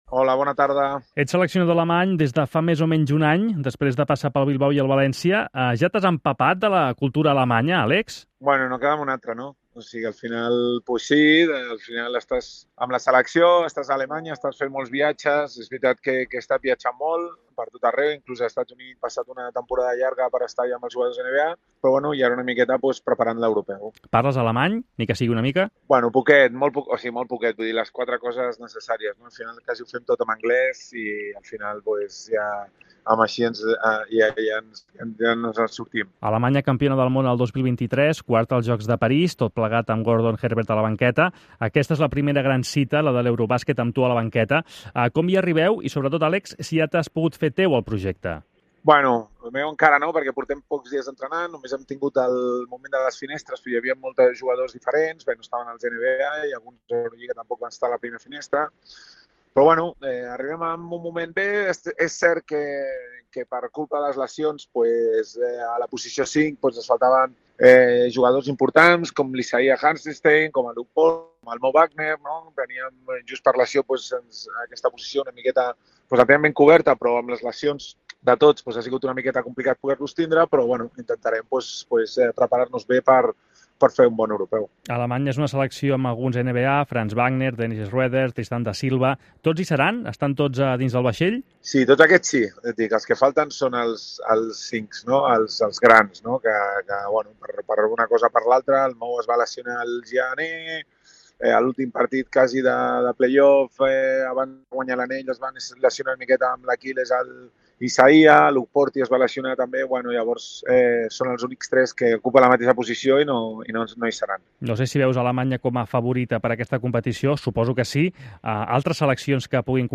L'entrenador català, actualment seleccionador d'Alemanya, atén la trucada del "Tot costa" abans de concentrar-se per preparar l'Eurobàsquet, que comença el 27 d'agost, i ha valorat el retorn de Ricky Rubio a la Penya.